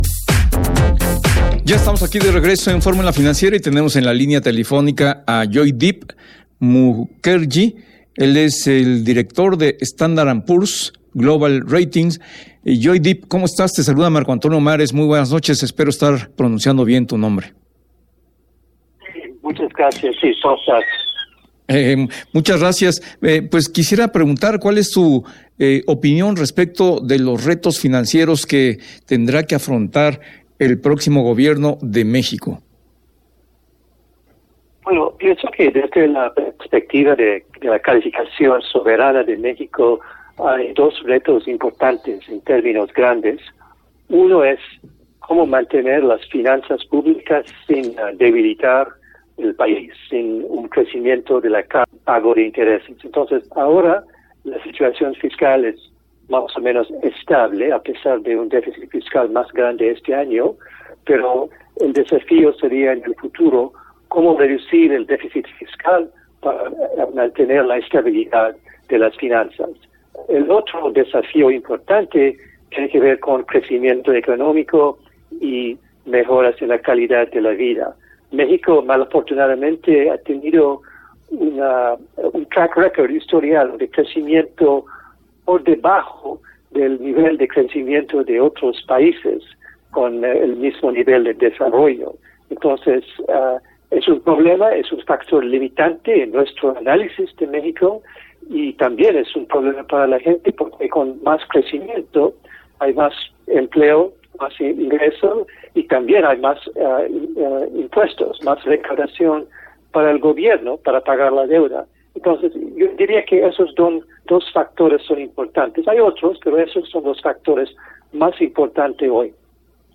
Entrevista en el programa F�rmula Financiera de Radio F�rmula